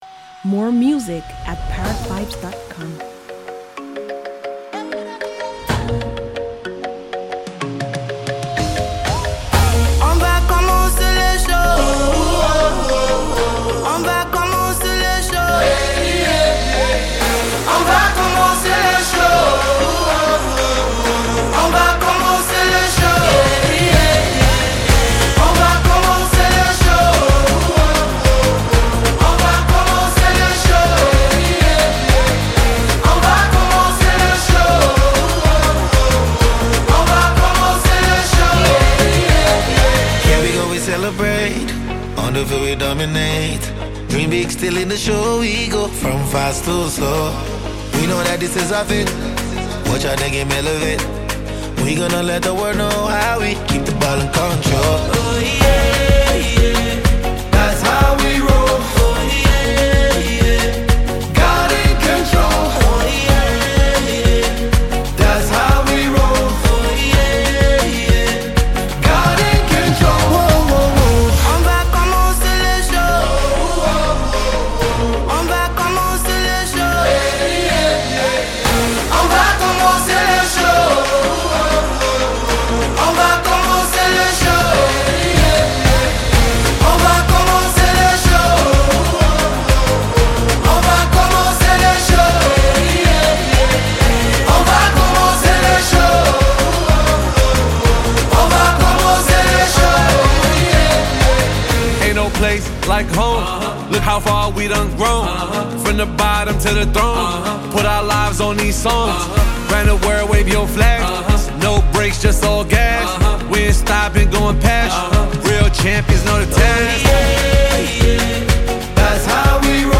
Effortlessly talented Nigerian vocalist
Moroccan-American rapper, singer, and songwriter